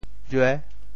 « 枘 » quel est le mot en Teochew ?